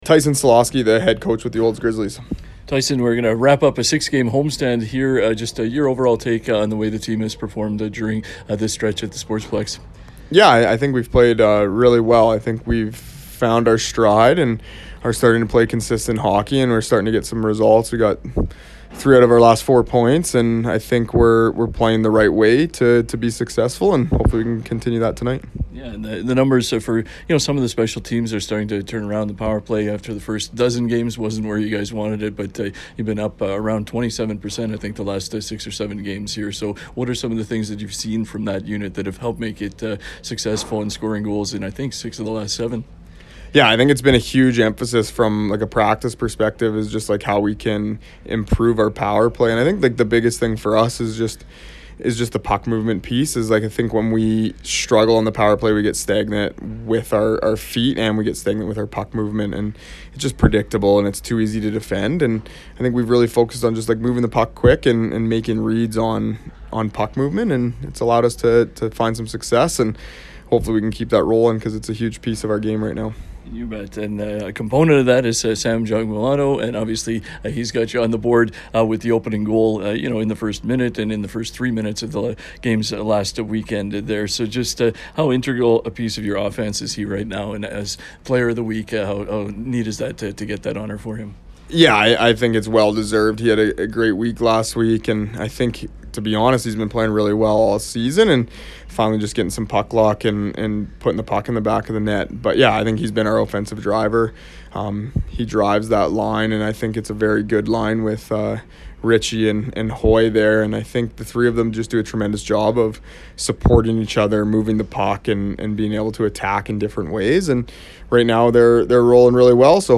Prior to the game
conversation